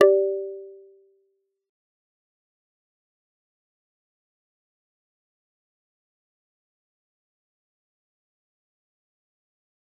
G_Kalimba-G4-f.wav